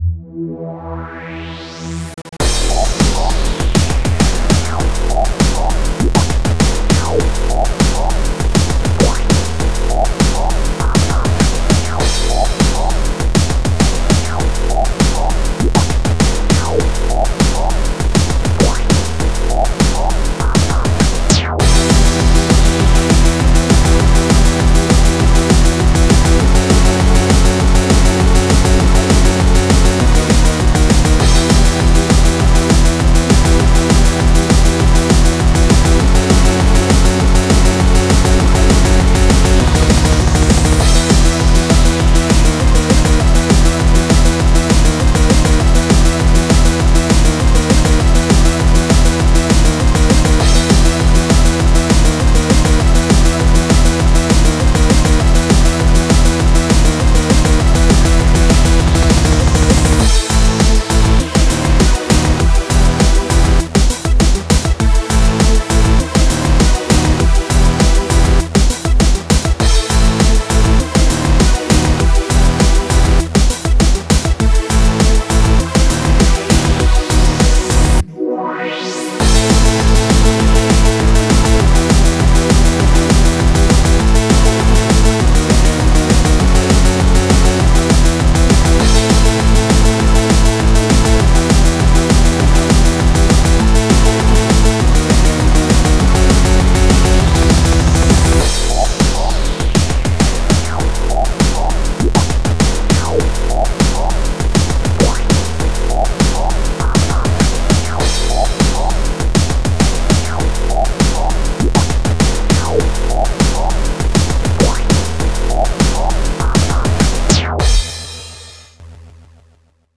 BPM100-400
Genre: Freeform Hardcore